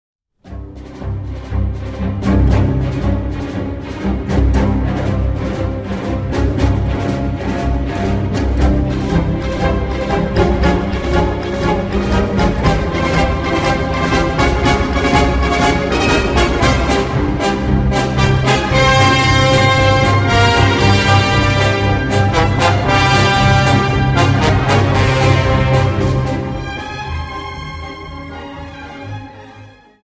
Dance: Paso Doble 59 Song